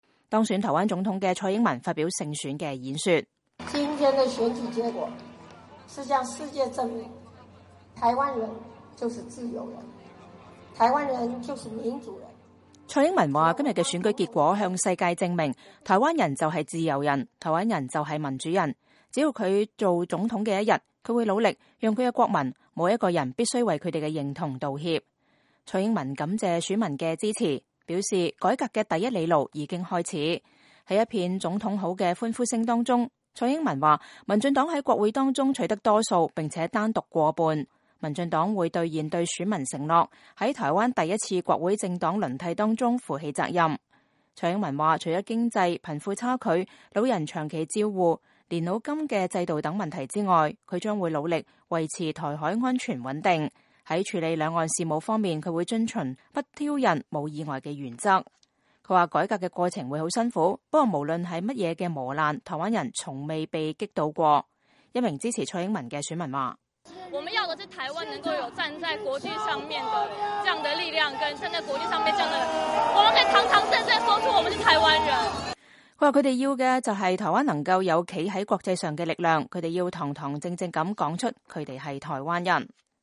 當選台灣總統的蔡英文發表勝選演說。
蔡英文以沙啞聲音感謝選民的支持，表示改革的第一哩路已經開始。